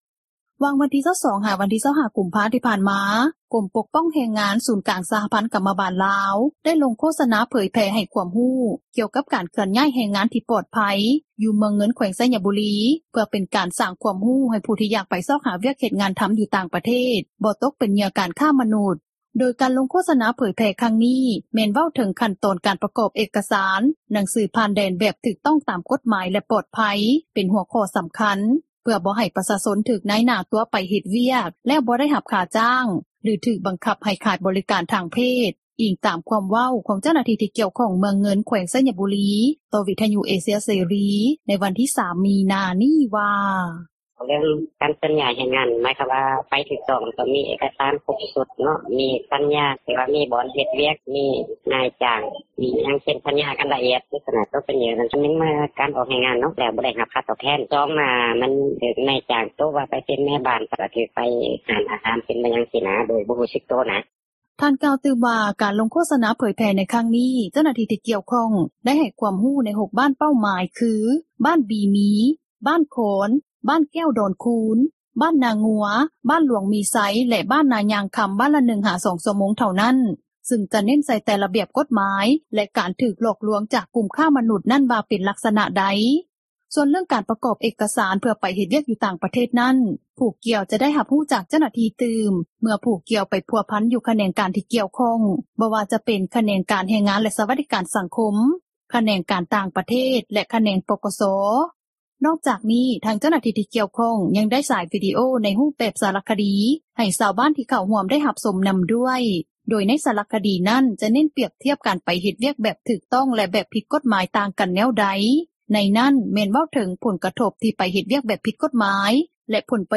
ດັ່ງເຈົ້າໜ້າທີ່ ທີ່ກ່ຽວຂ້ອງ ແຂວງໄຊຍະບູຣີ ກ່າວໃນມື້ດຽວກັນນີ້ວ່າ:
ດັ່ງຊາວບ້ານ ຢູ່ບ້ານແກ້ວດອນຄູນ ທີ່ເຂົ້າຮ່ວມຮັບຟັງ ການໂຄສະນາເຜີຍແຜ່ຄວາມຮູ້ ກ່ຽວກັບການເຄື່ອນຍ້າຍແຮງງານທີ່ປອດພັຍ ກ່າວໃນມື້ດຽວກັນນີ້ວ່າ: